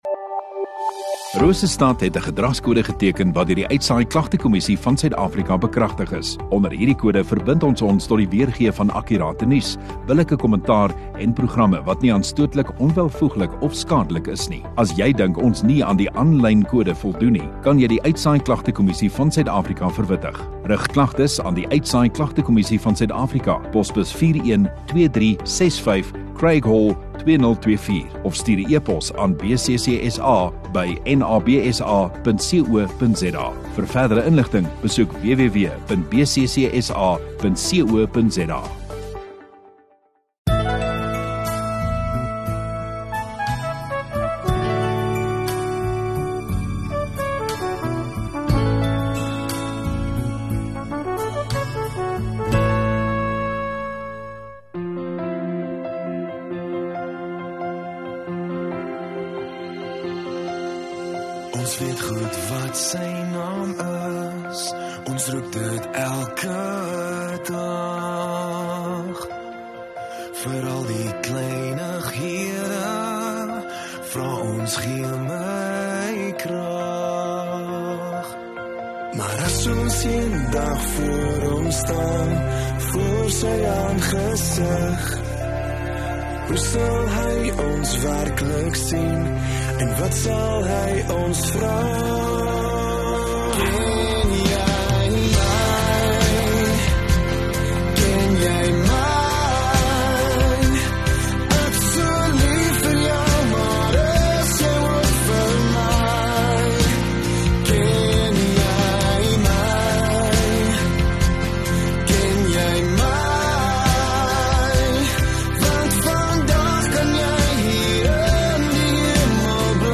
23 Aug Vrydag Oggenddiens